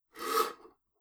scrape2.wav